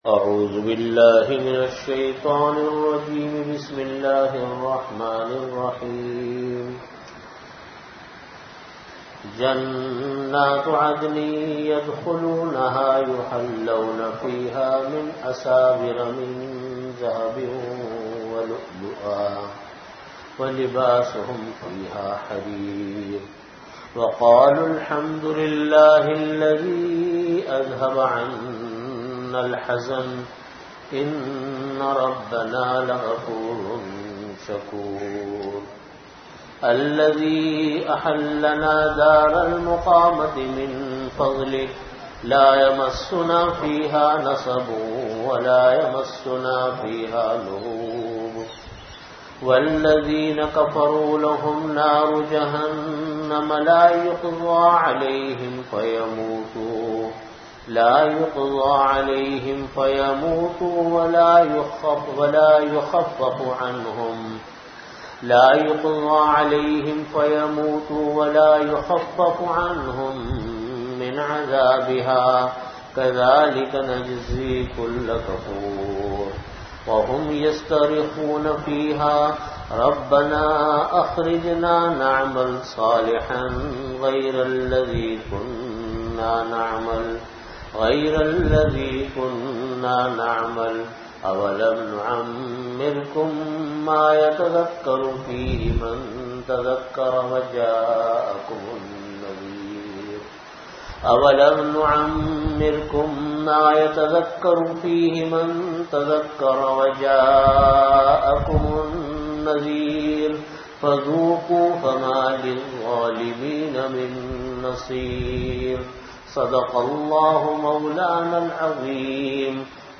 Category: Tafseer
Venue: Jamia Masjid Bait-ul-Mukkaram, Karachi